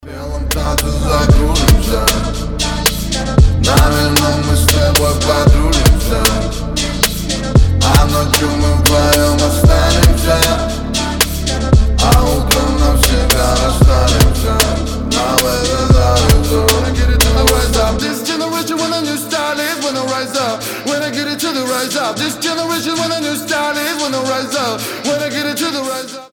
• Качество: 320, Stereo
Cover
качающие
речитатив